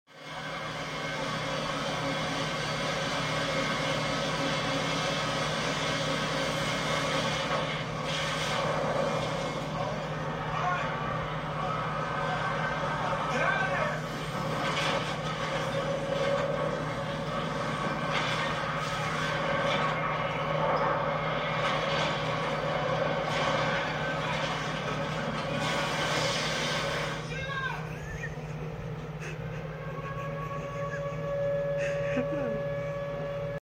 The way ice cube was screaming "Sheila!"